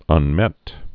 (ŭn-mĕt)